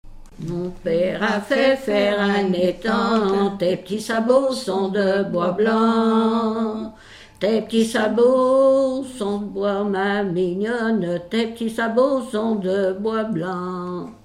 gestuel : à marcher
Genre laisse
Pièce musicale inédite